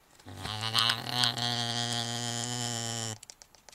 Звуки нутрии (Myocastor coypus)